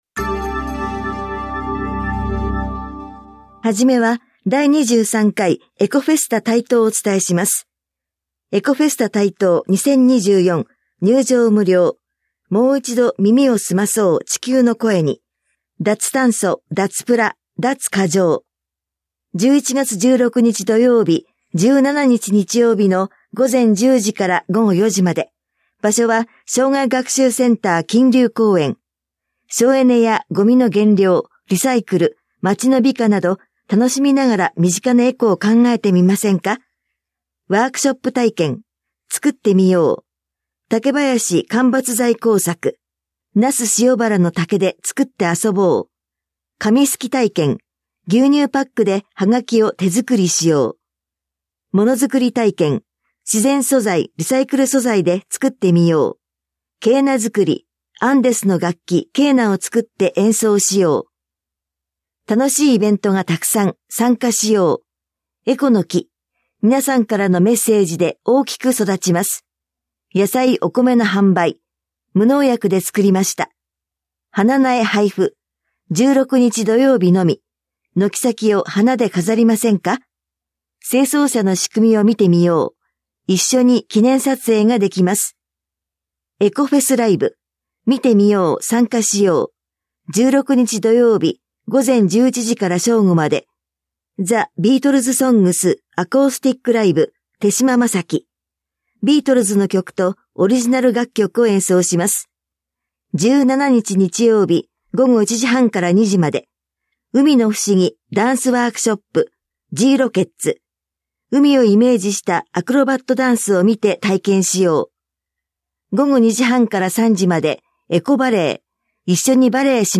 広報「たいとう」令和6年10月20日号の音声読み上げデータです。